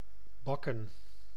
Ääntäminen
IPA: /ˈbɑ.kə(n)/